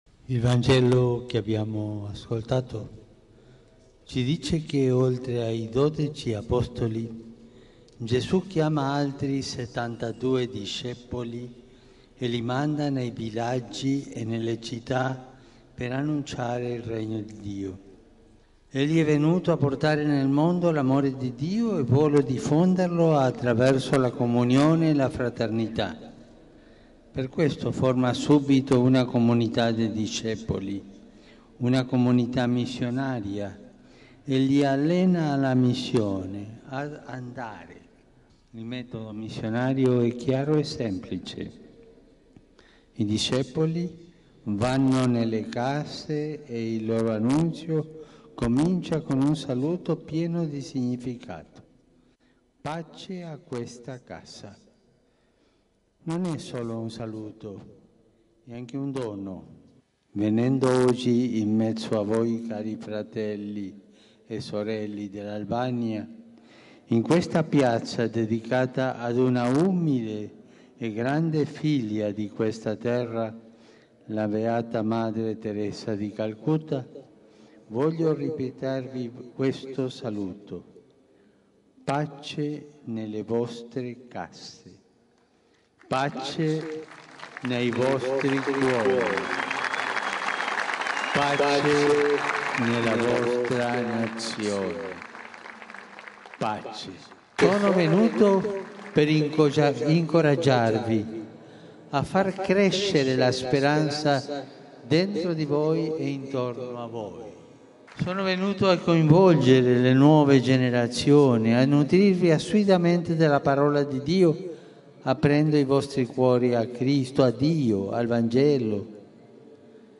MP3 Äiti Teresan aukiolla toimitetussa messussa paavi Franciscus muistutti kuinka Jeesus oli muodostanut apostolien yhteisön, kaksitoista ja toiset seitsemänkymmentäkaksi.